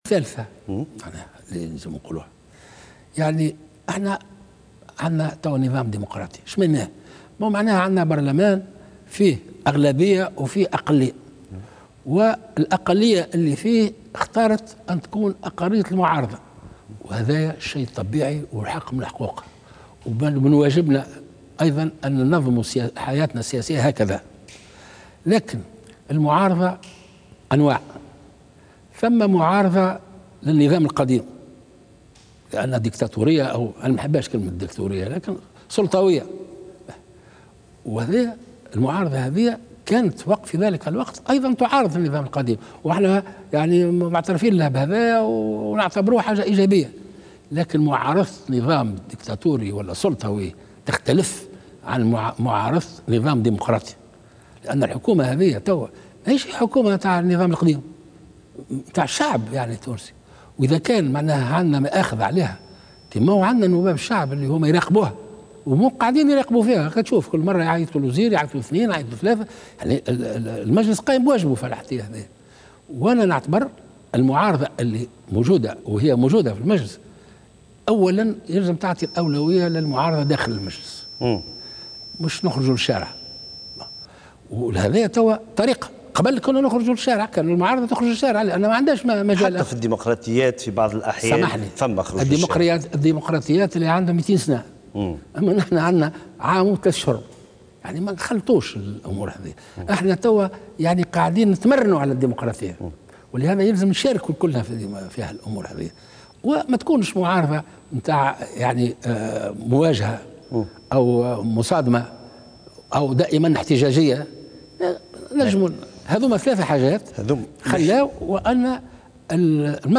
وأضاف قائد السبسي في حوار تلفزي على القناة الوطنية الأولى أن هذه الحكومة ليست حكومة النظام القديم بل انتخبها الشعب، كما أن البرلمان يراقب أعمالها.